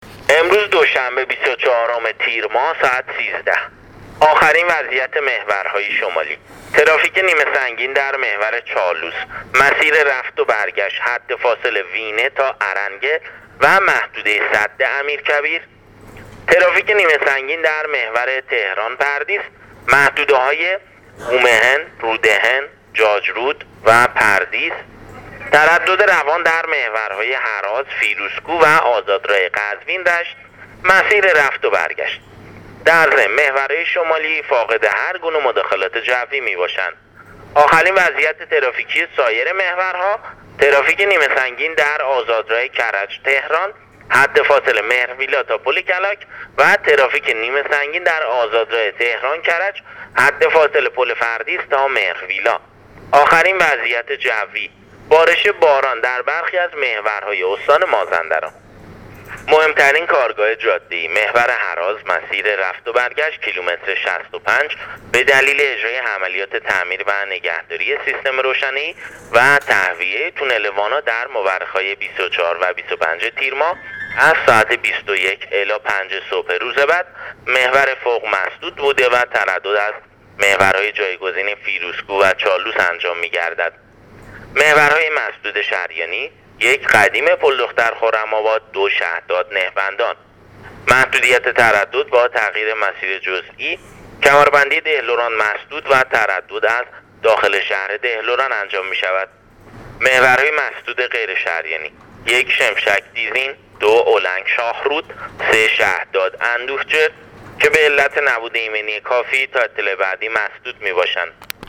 گزارش رادیو اینترنتی وزارت راه و شهرسازی از آخرین وضعیت‌ ترافیکی راه‌های کشور تا ساعت ۱۳ بیست و چهارم تیرماه/ تردد کند در محور چالوس و تهران - پردیس/ ترافیک نیمه سنگین در آزادراه کرج - تهران و بالعکس